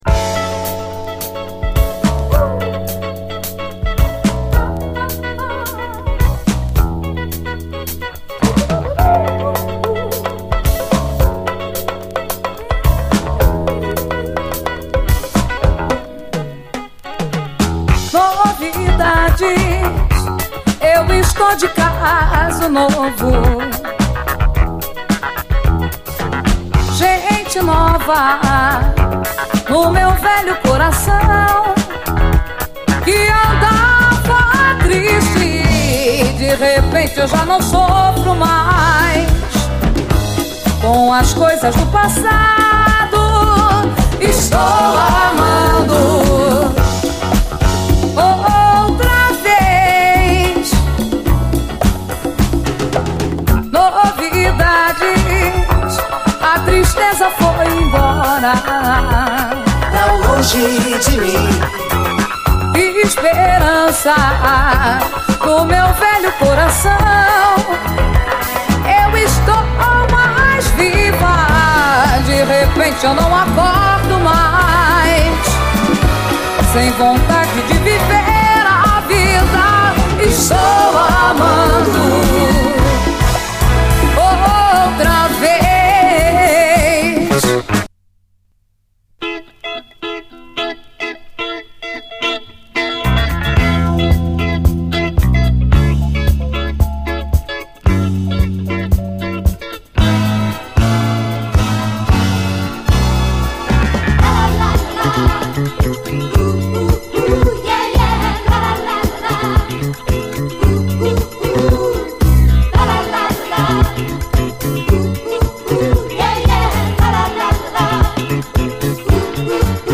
DISCO, BRAZIL